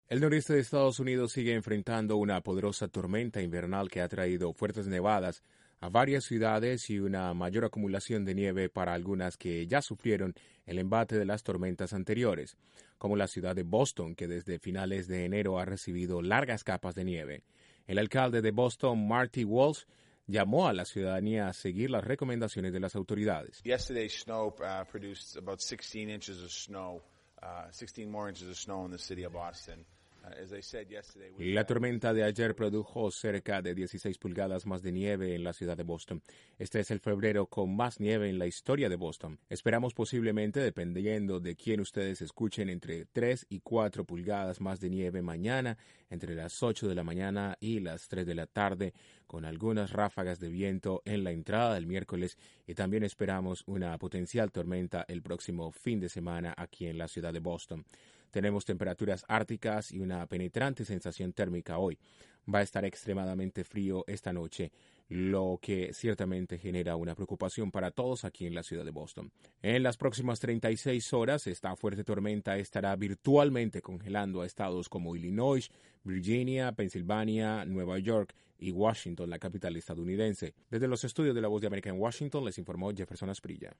La Tormenta invernal Octavia amenaza con dejar varias pulgadas de nieve y varias ciudades podrían alcanzar niveles récord de acumulación al noroeste del Estados Unidos. Desde la Voz de América en Washington informa